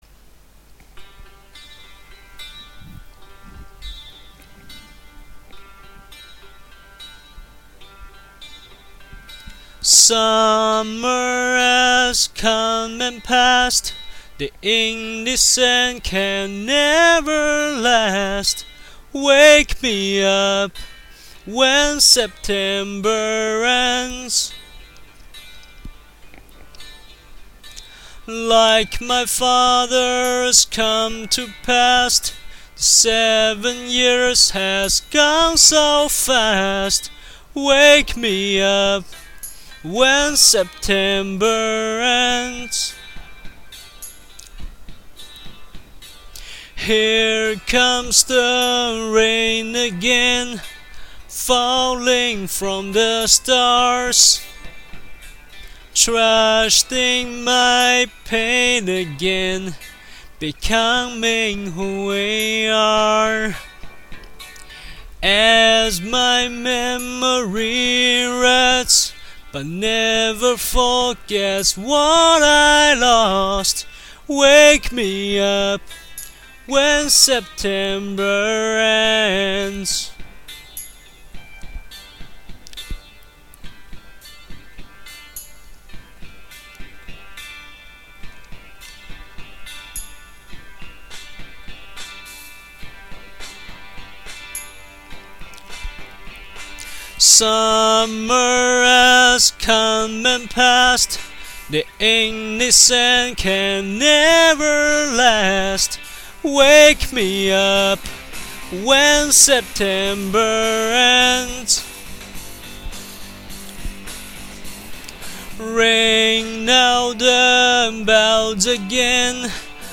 录音工具很烂，请大家将就一下。